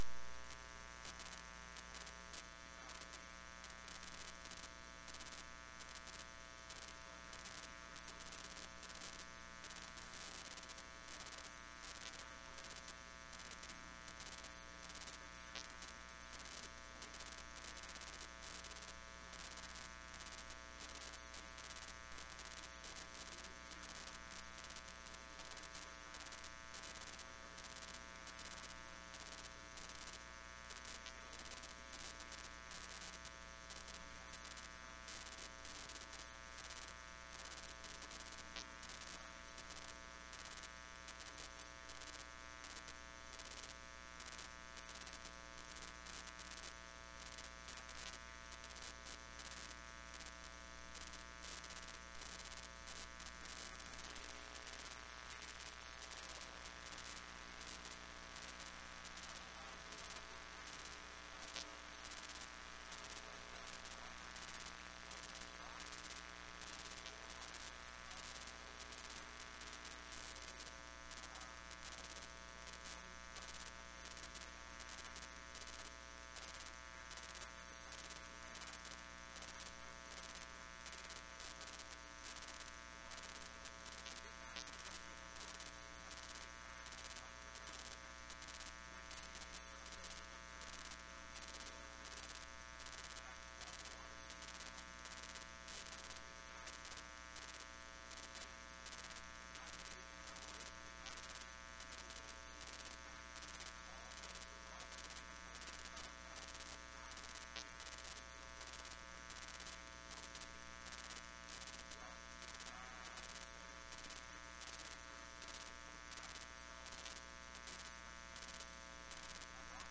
Sermons | Fort George Baptist Church